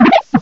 cry_not_watchog.aif